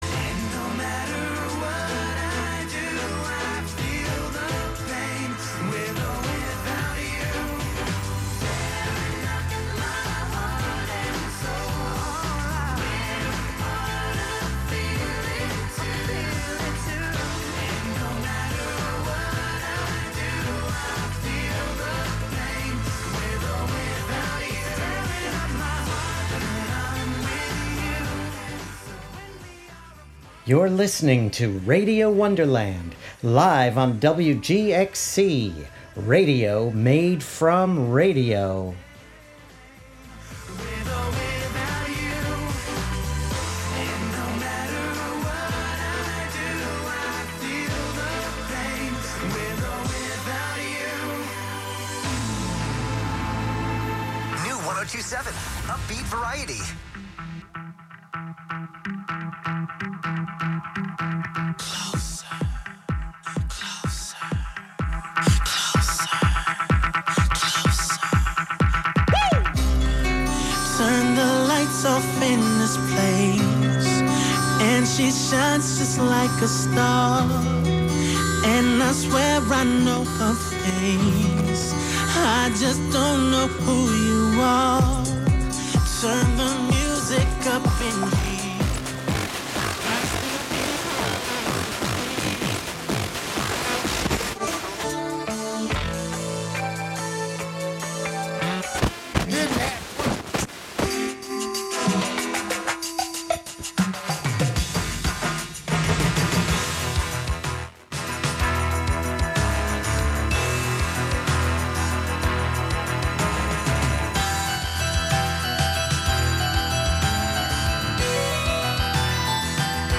11am Live from Brooklyn, New York
instant techno